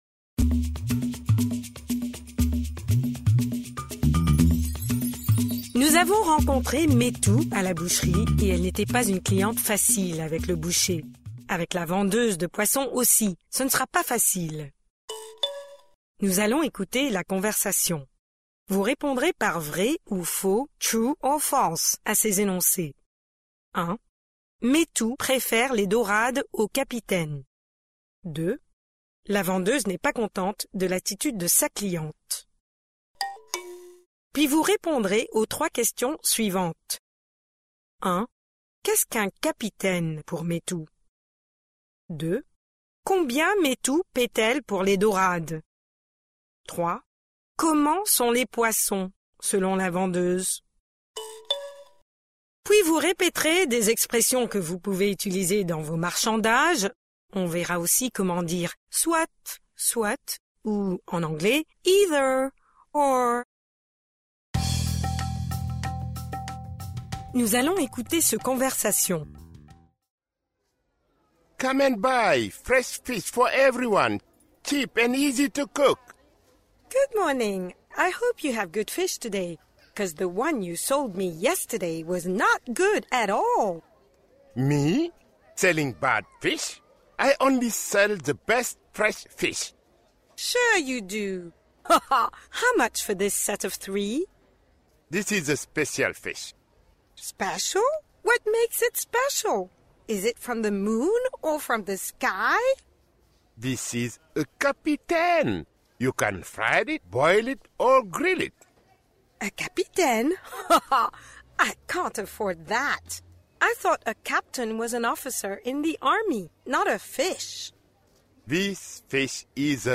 Avec la vendeuse de poisson aussi, ce ne sera pas facile ! Pre-listening: Nous allons écouter la conversation.